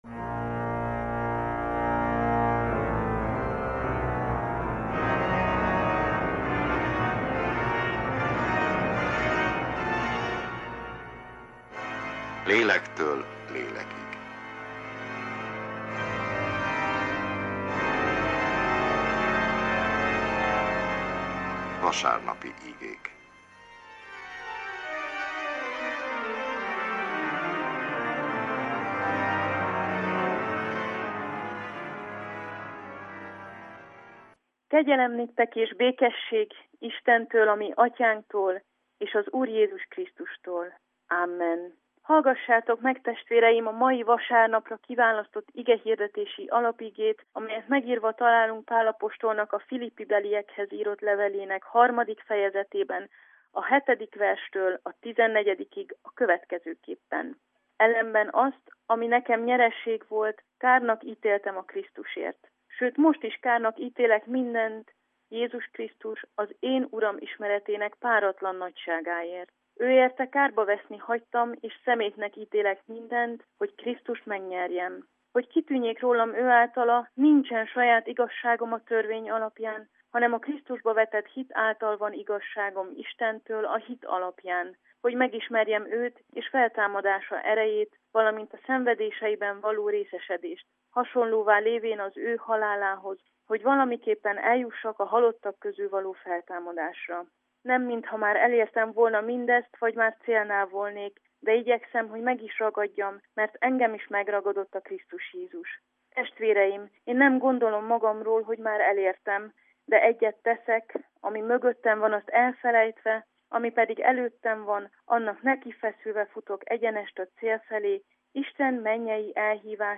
Evangélikus igehirdetés, augusztus 21.